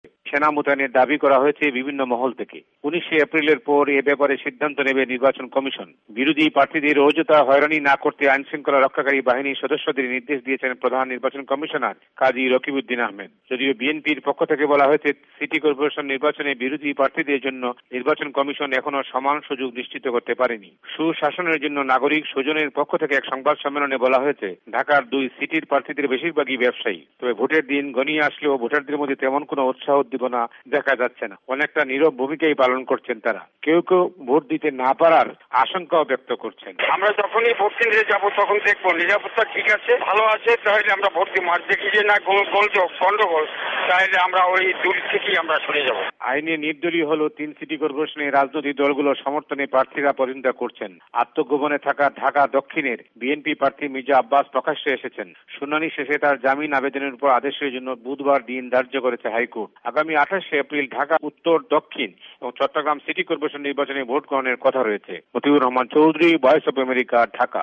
বাংলাদেশে ঘনিয়ে আসছে তিন সিটি কর্পোরেশনের নির্বাচন-জমে উঠেছে প্রচারণা যুদ্ধ। অভিযোগ, পাল্টা অভিযোগও রয়েছে; বিরোধি সমর্থকদের অভিযোগ পুলিশ তাঁদের হয়রানী করছে। এসব তথ্য দিয়ে রিপোর্ট পাঠিয়েছেন ঢাকা থেকে